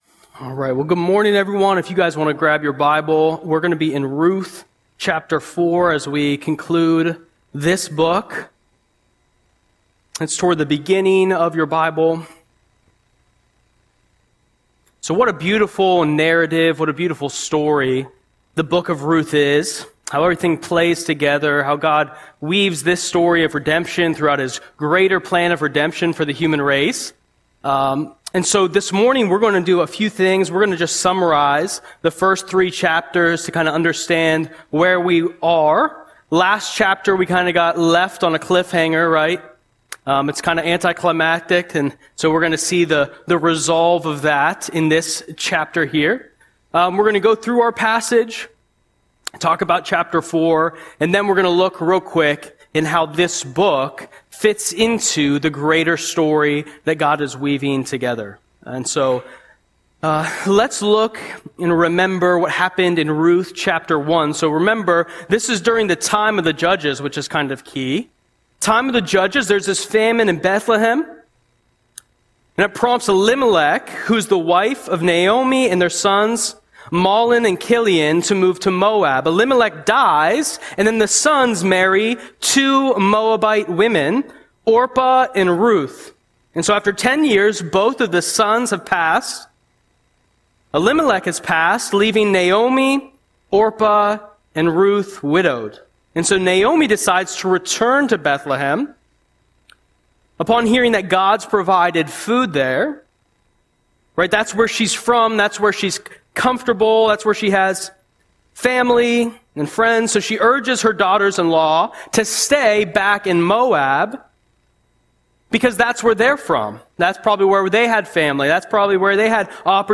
Audio Sermon - September 14, 2025